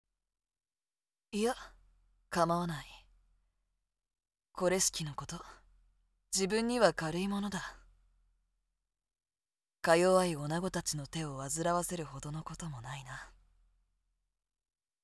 【サンプルセリフ】
（かぶってます）